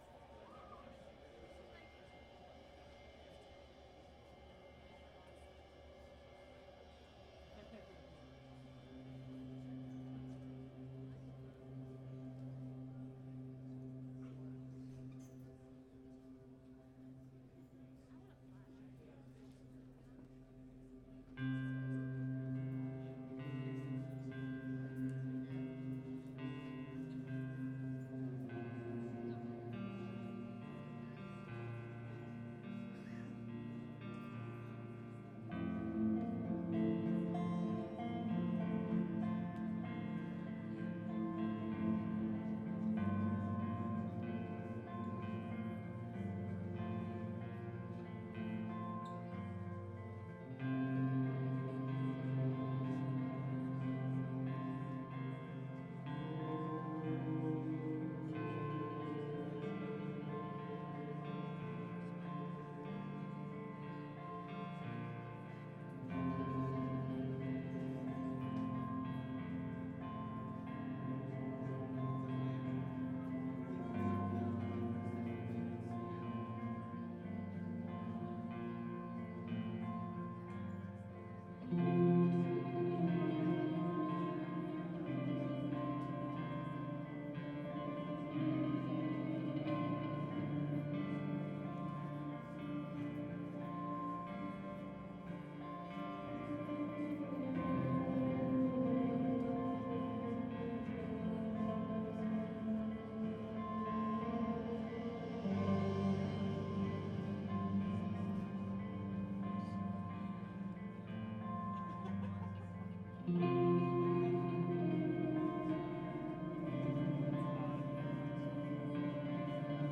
post-rock